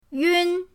yun1.mp3